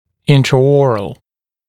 [ˌɪntrə»ɔːrəl][ˌинтрэ’о:рэл]внутриротовой